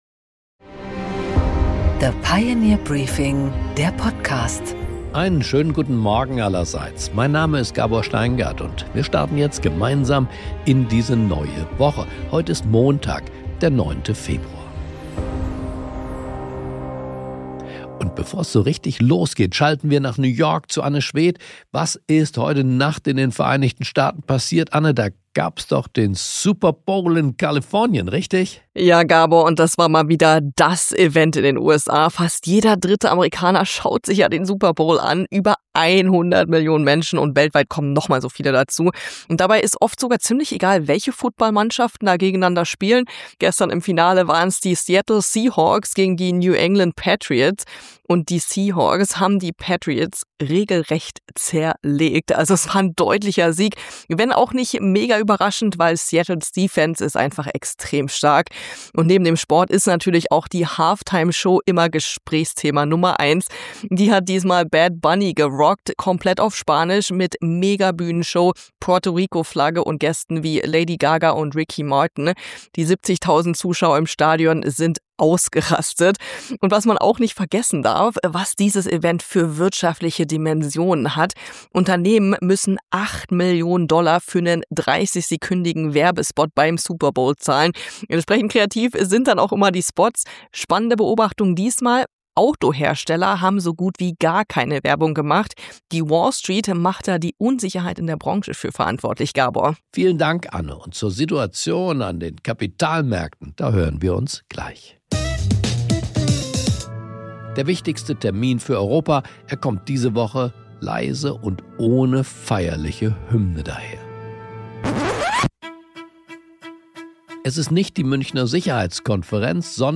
Gabor Steingart präsentiert das Pioneer Briefing.
Im Gespräch: Carsten Linnemann, MdB und CDU-Generalsekretär, erklärt, wo er die Partei und die Regierung aktuell sieht und wie er die Reformagenda der Regierung auf die Straße bringen will.